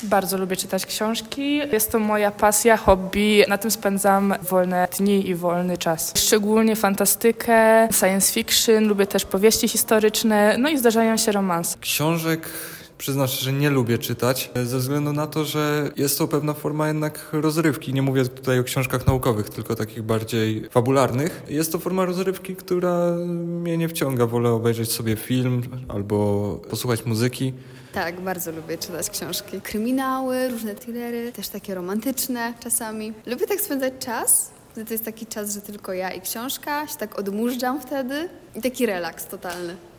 O tym, czy studenci lubią czytać książki i jakie konkretnie, zapytaliśmy ich samych.